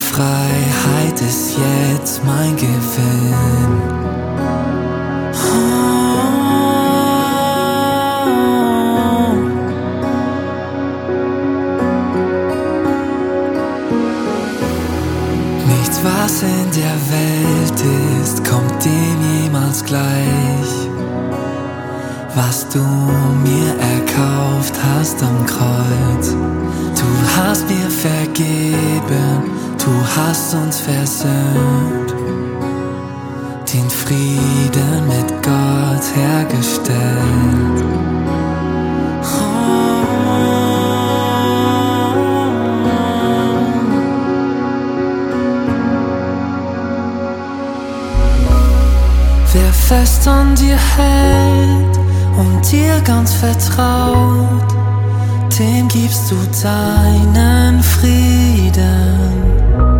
Kraftvoll und intensiv.
Die Songs tragen ein neues Gewand im singbaren Stil.
Gesang.